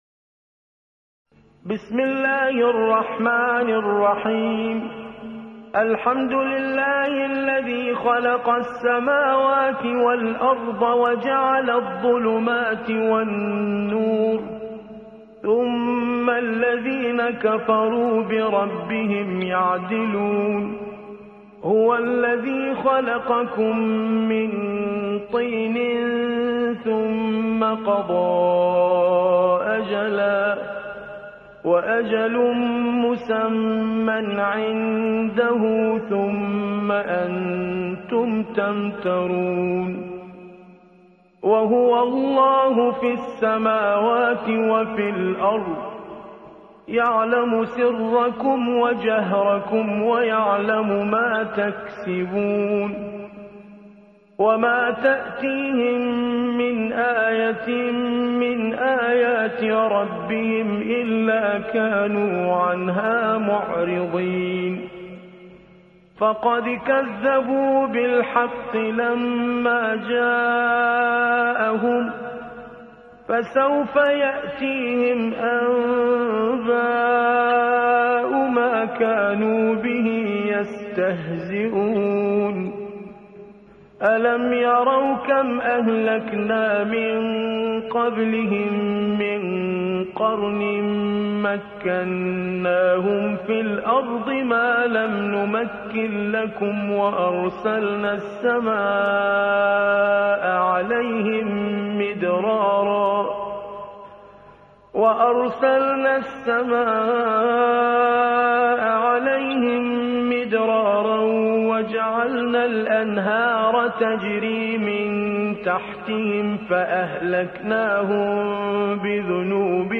6. سورة الأنعام / القارئ